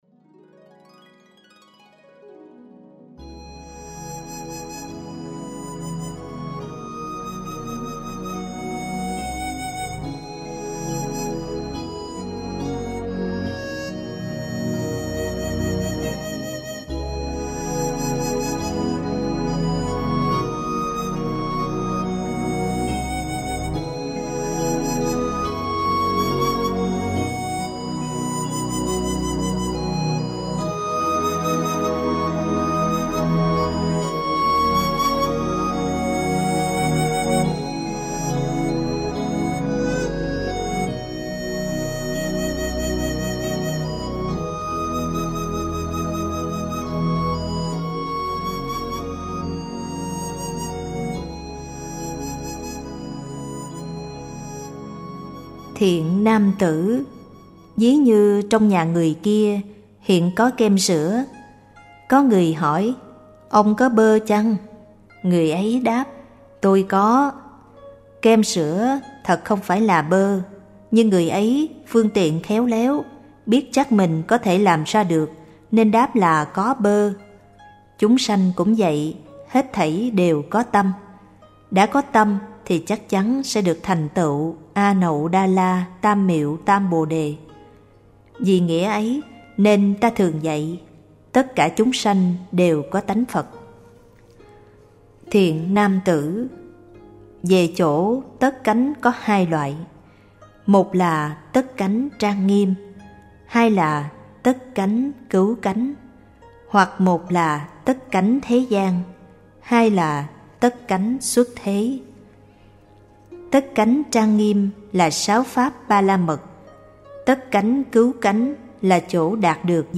Niệm Hồng Danh A Di Đà Phật